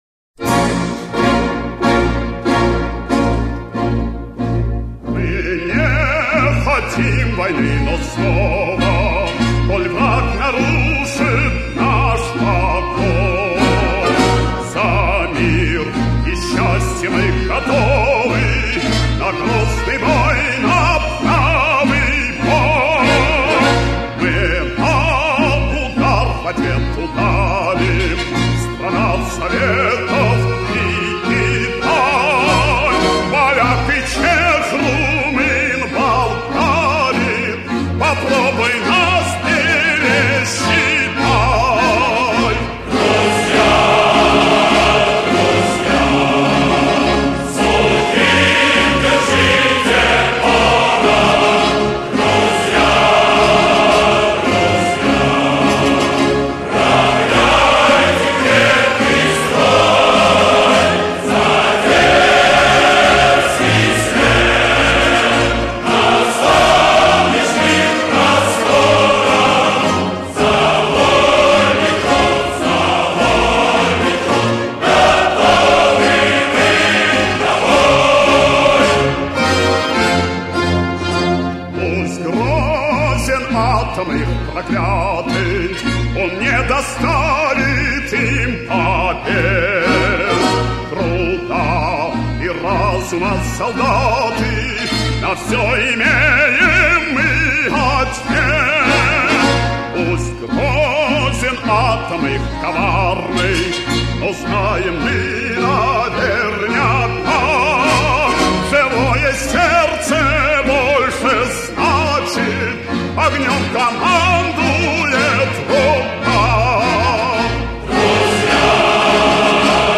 Редкая запись в отличном качестве.